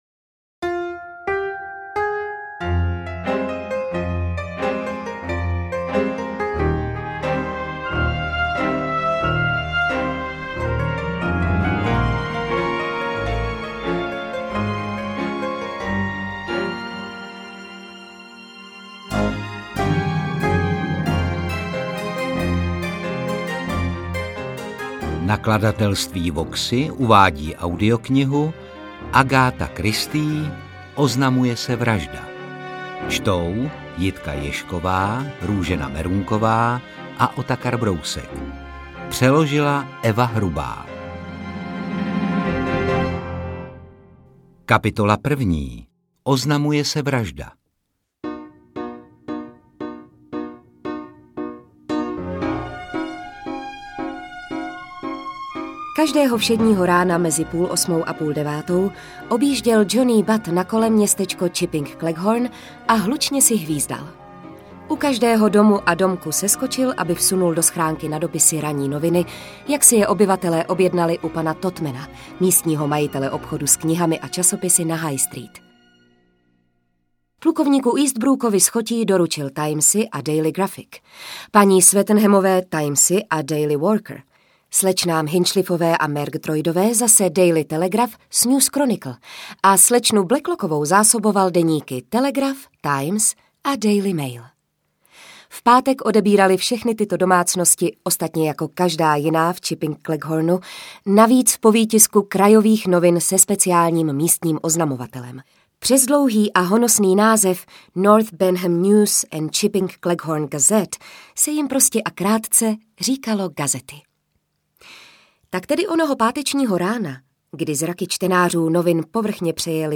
AudioKniha ke stažení, 25 x mp3, délka 8 hod. 48 min., velikost 488,4 MB, česky